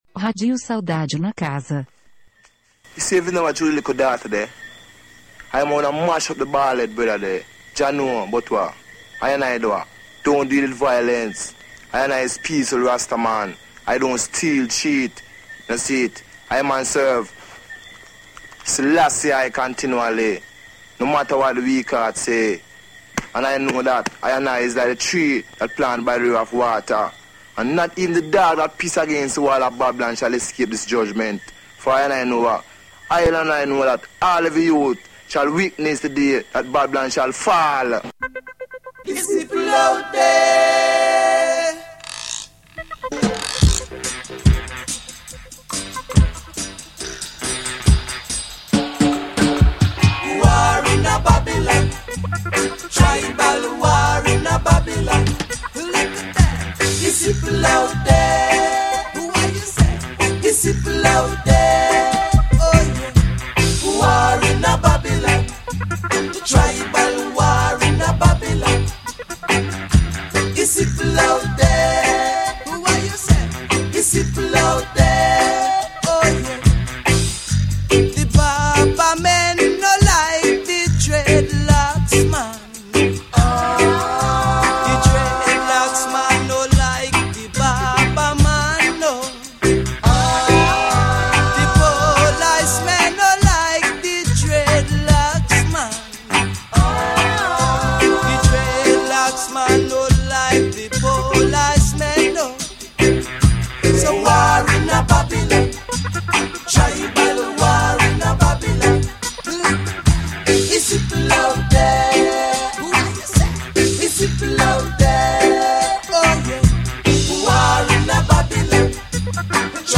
Heavyweight roots vibe tonight – enjoy.